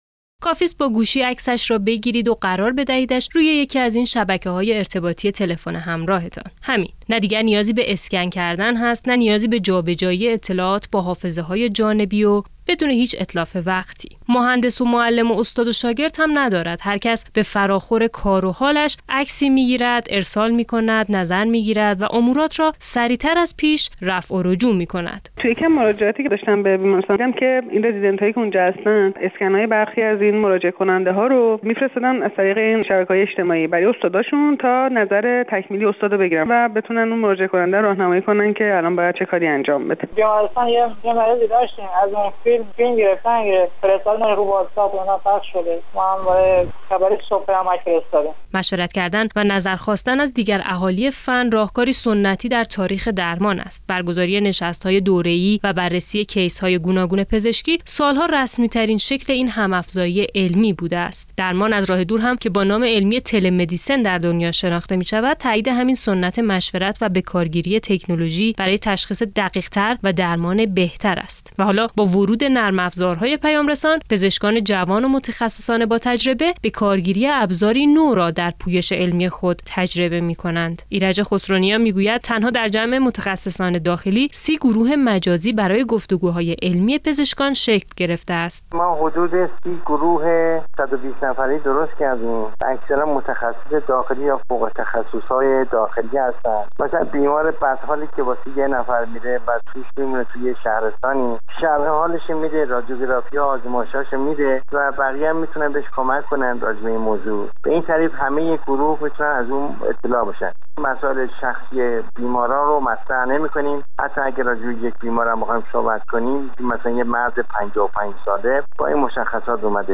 گزارش شنیدنی؛ ارتباطات مجازی و تهدید حریم های پزشکی - تسنیم